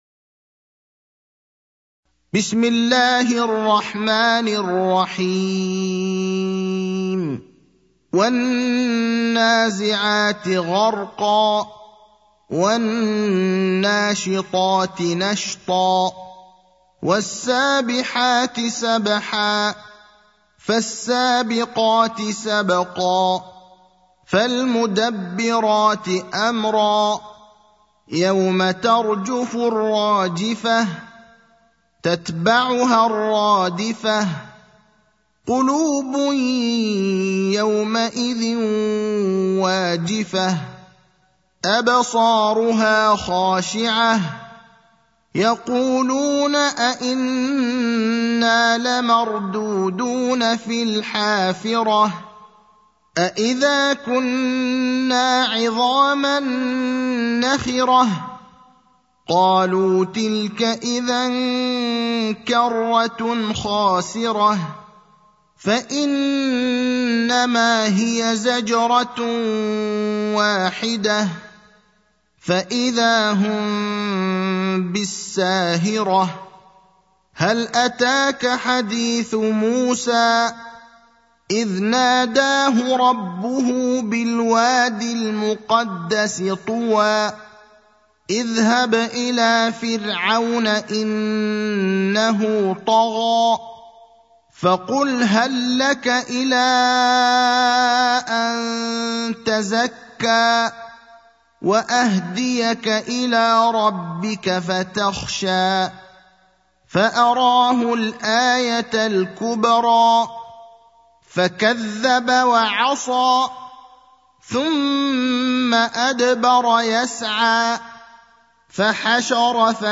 المكان: المسجد النبوي الشيخ: فضيلة الشيخ إبراهيم الأخضر فضيلة الشيخ إبراهيم الأخضر النازعات (79)